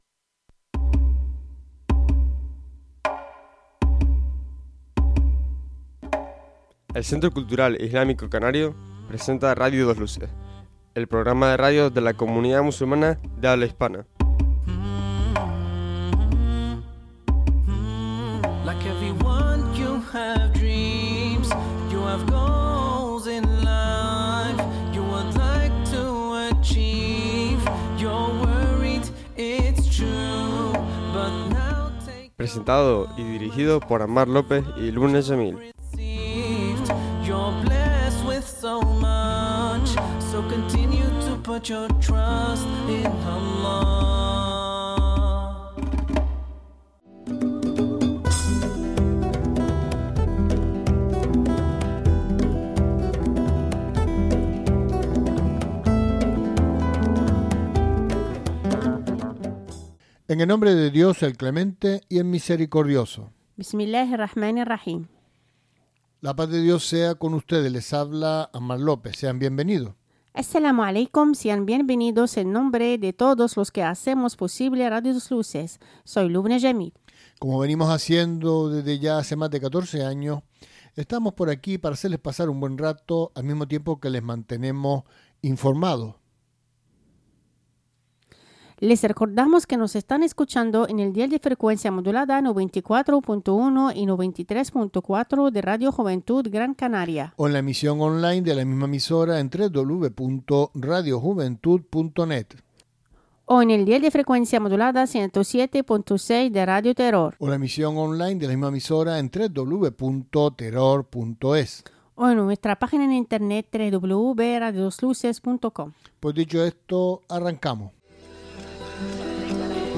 El programa completo en Radio 2 Luces. Con todas las secciones de actualidad, debate, entrevistas, música,...